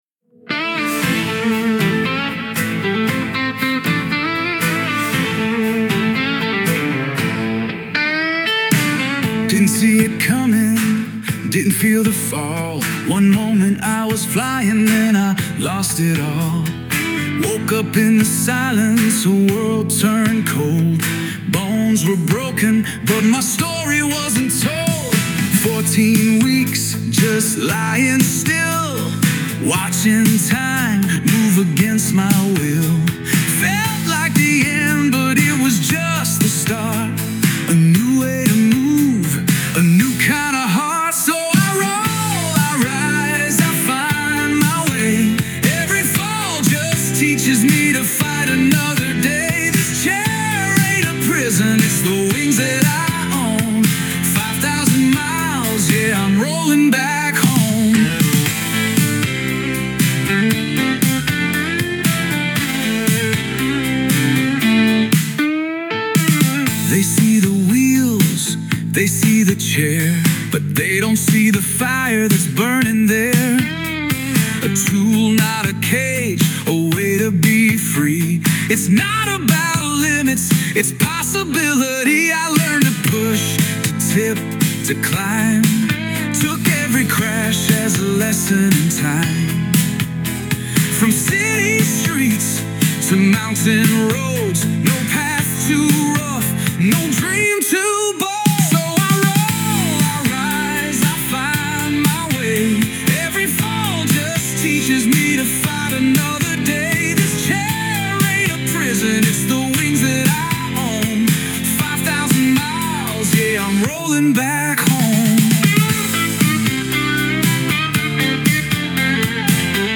It’s March 2025 and I asked AI to write a song about my Rolling Back Home adventure.